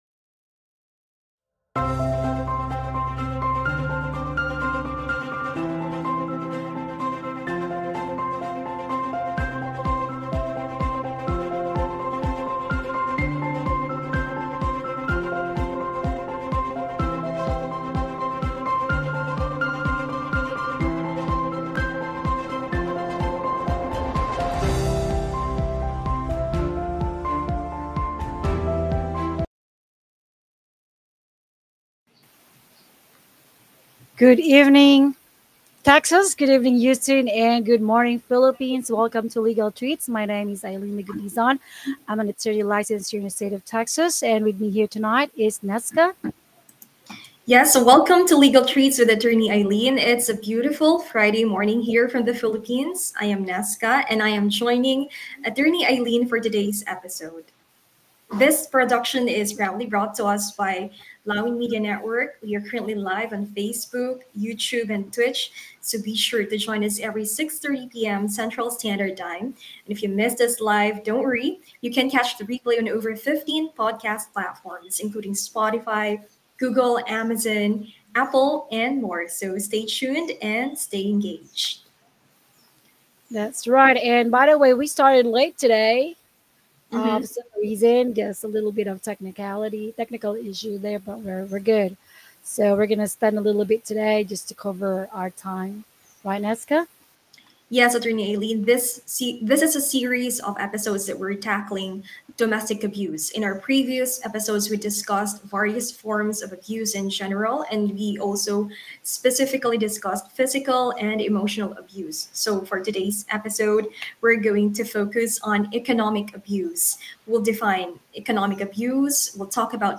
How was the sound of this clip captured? Tune in every Friday, at 6:30 PM CST for a live episode of 'Legal Treats'